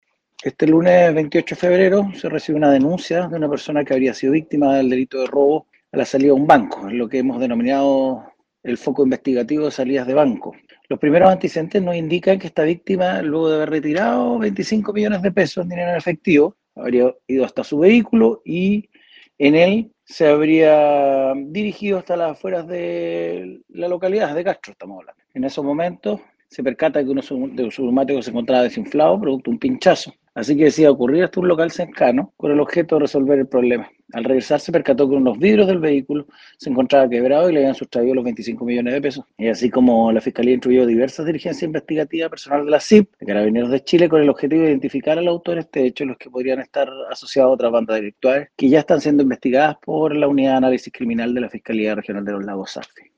El fiscal Rodrigo Oyarzún, jefe de la Unidad de Análisis Criminal de la Fiscalía Regional de Los Lagos, se refirió a este hecho ilícito que tuvo lugar a la salida de una casa bancaria en la ciudad capital de la provincia de Chiloé.